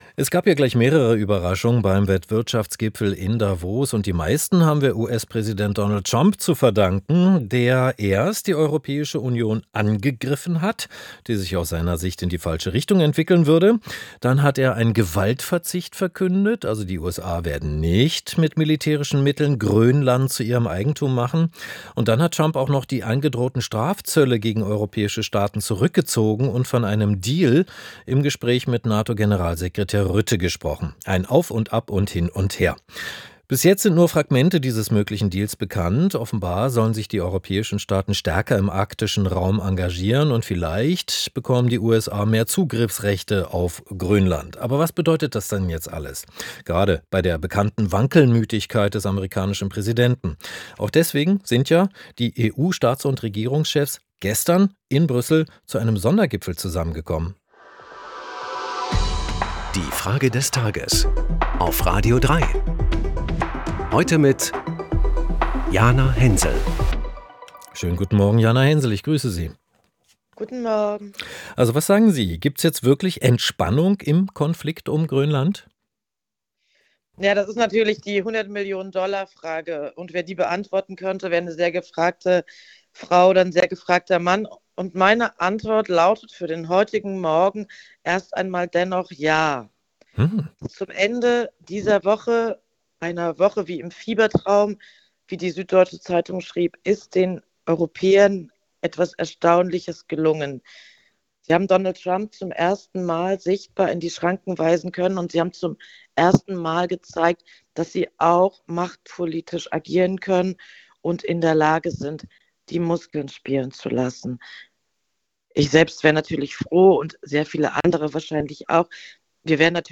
Zehn starke Stimmen im Wechsel "Die Frage des Tages" – montags bis freitags, immer um 8 Uhr 10.
Autorin Jana Hensel.